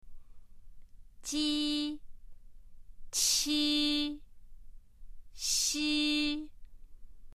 （　）の母音をつけた第一声の発音を聞いてみましょう。
ji-qi-xi.mp3